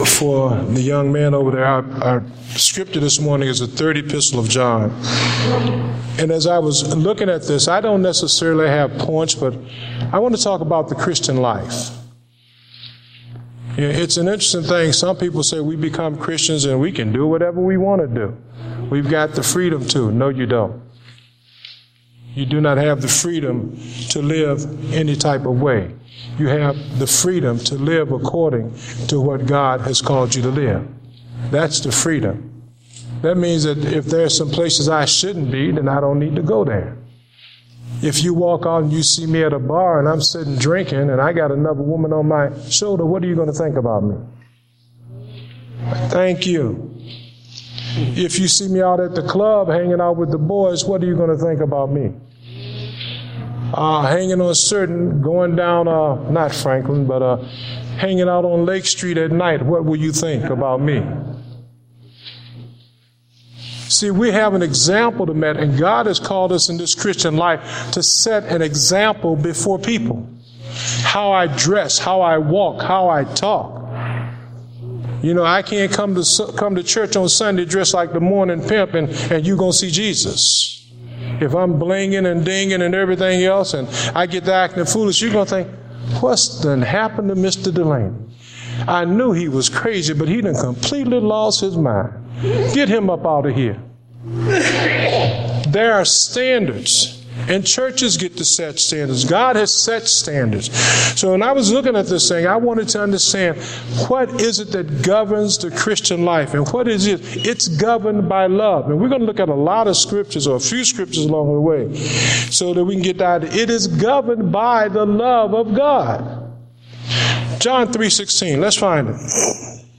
Date: December 14, 2014 (Morning Service)